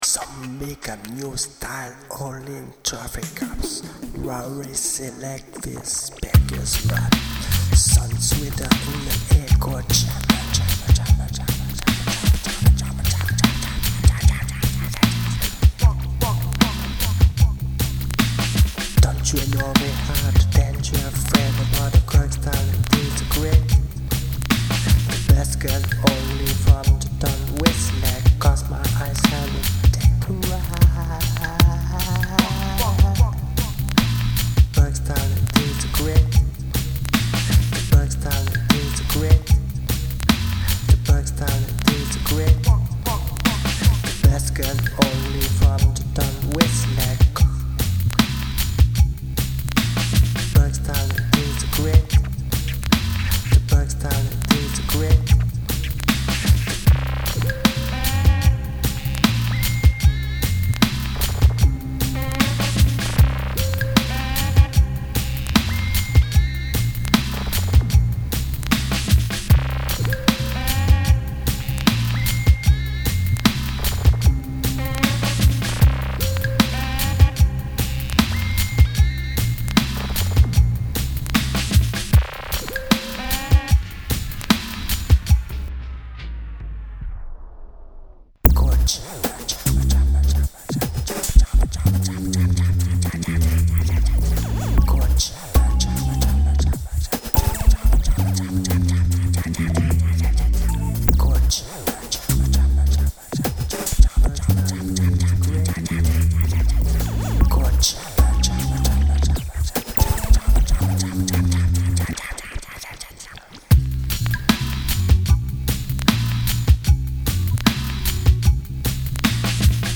Parties audios faites en une prise (à refaire quand je pourrais)
triphoop.mp3